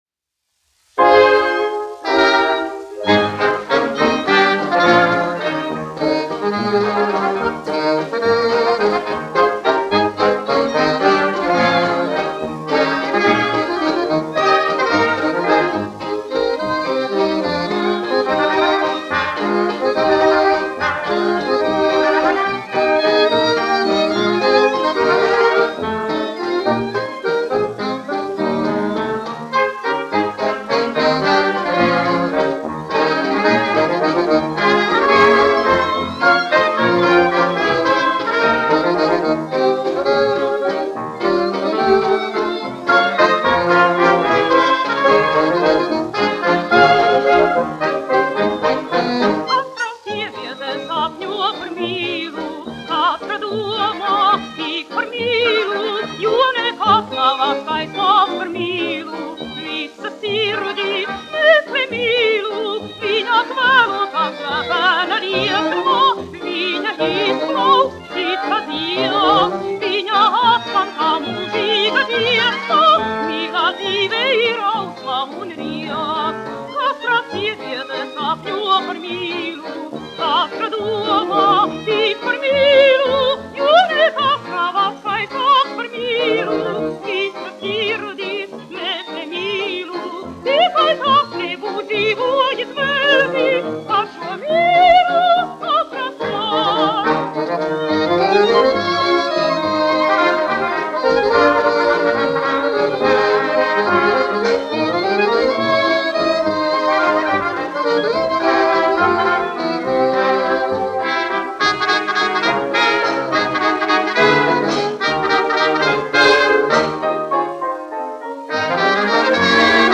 1 skpl. : analogs, 78 apgr/min, mono ; 25 cm
Kinomūzika
Skaņuplate